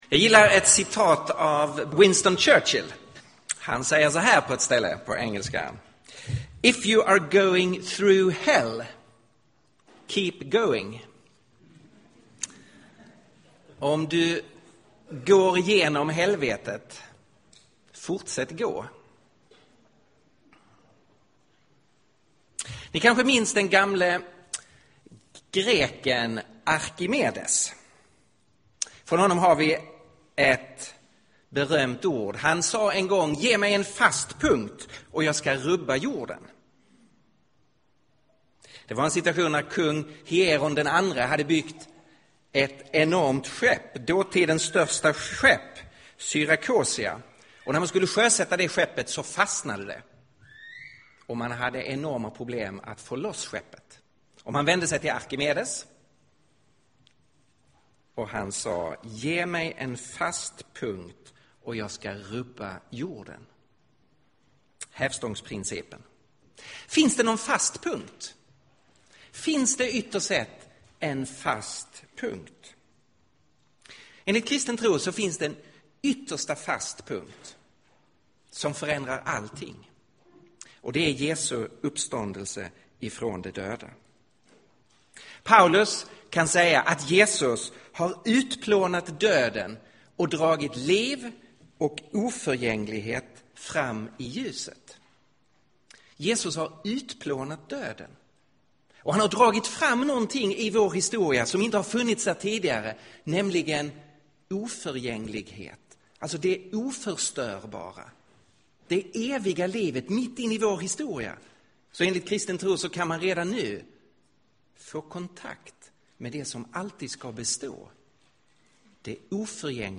Inspelad i Sollentuna Pingstkyrka 2012-10-14.